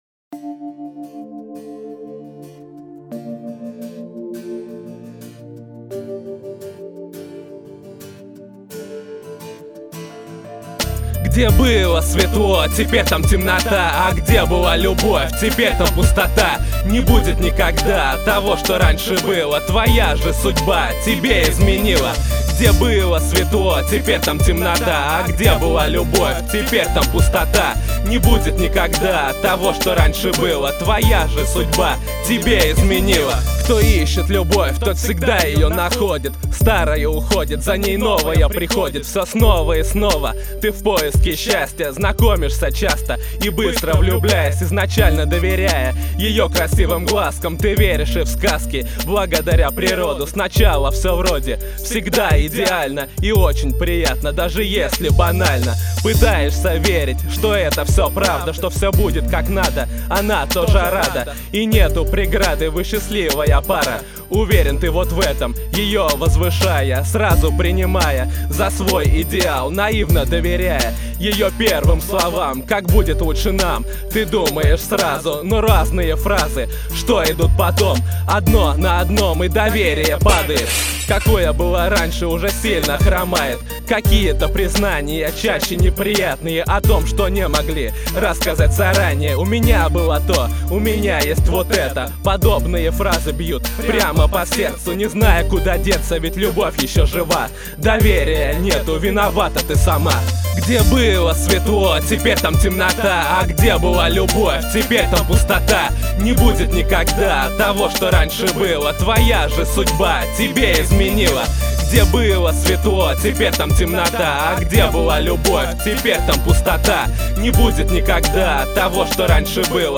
(имеется в мп3 записи рэпчик качаем не стисняемся)